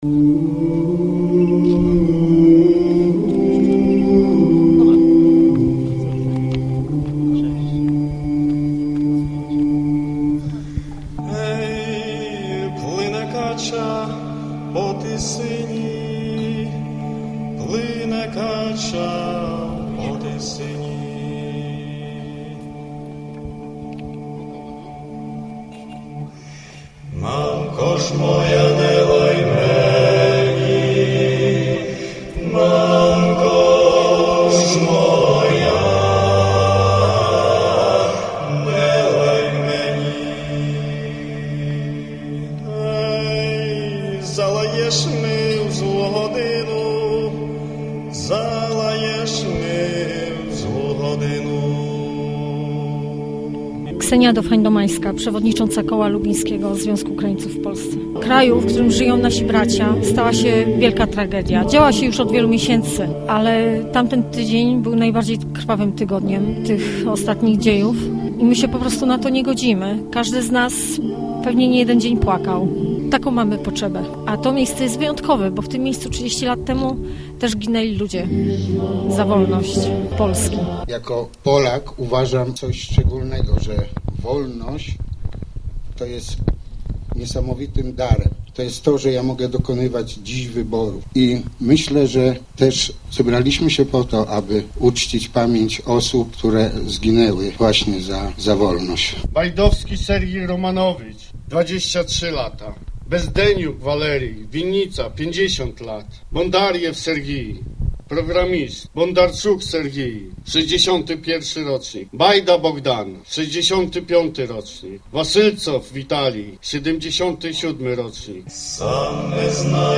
ukrainelubin.jpgPrzedstawiciele Związku Ukraińców w Polsce z Lubina i Legnicy, członkowie Stowarzyszenia Łemków uczcili pamięć zamordowanych obywateli Ukrainy na kijowskim Majdanie. Apel pamięci odbył się na Wzgórzu Zamkowym, nieopodal pomnika Solidarności.
W uroczystości wzięło udział około 50 osób.